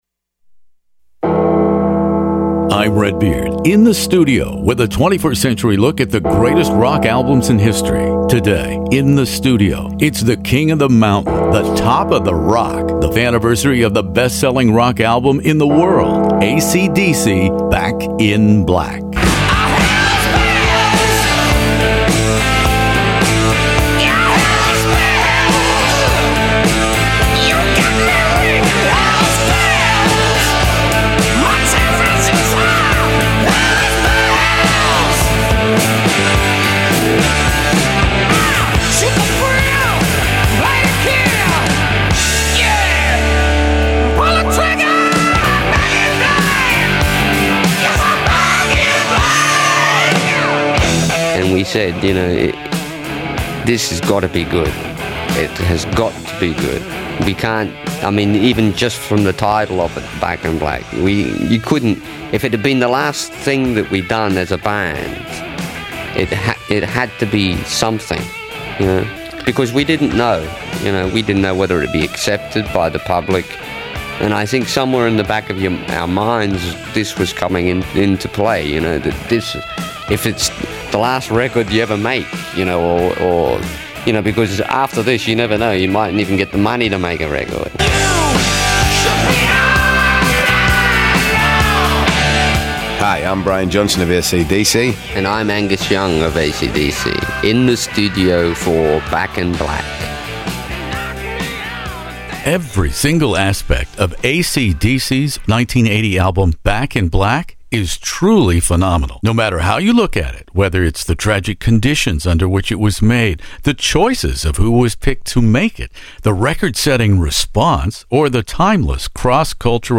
ACDC "Back in Black" interview with Angus Young, Brian Johnsom, and archival interview of Malcolm Young In the Studio